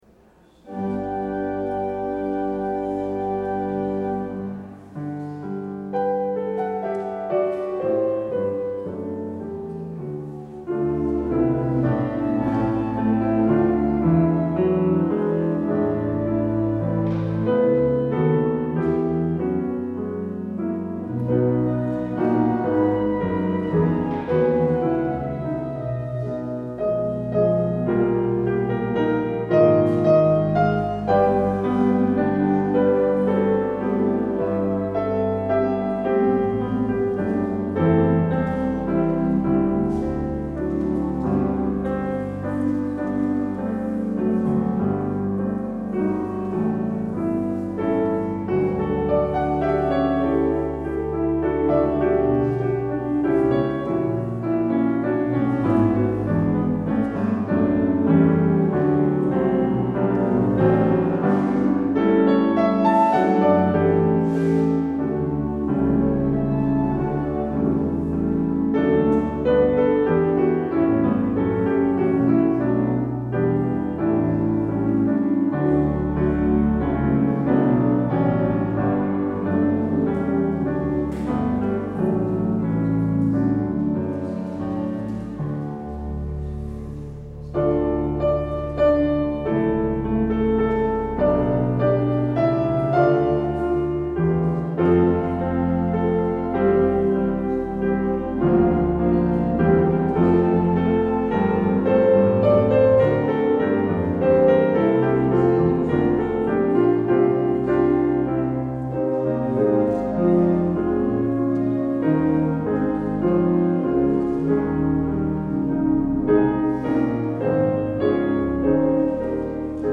 Luister deze kerkdienst hier terug
Het openingslied is Psalm 89: 1 en 7.
Als slotlied hoort u NLB 90a: 1, 5 en 6.